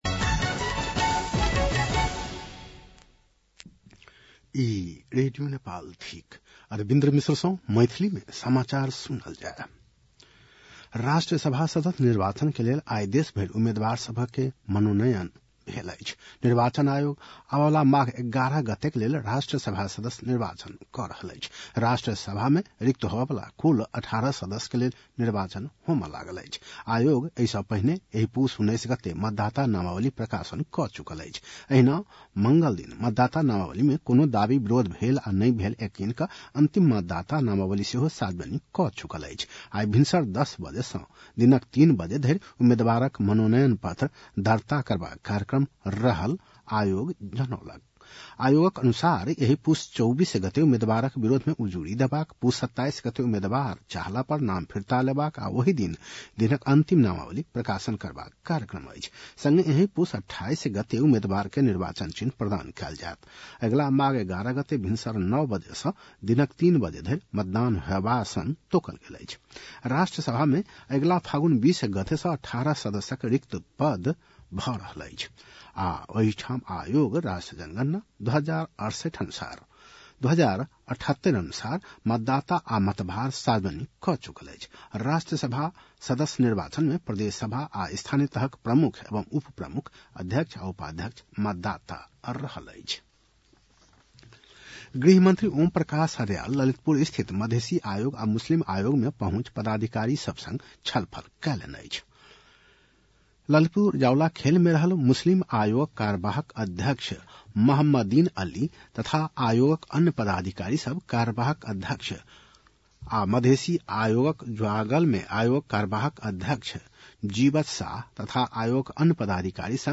मैथिली भाषामा समाचार : २३ पुष , २०८२
MAITHALI-NEWS-09-23.mp3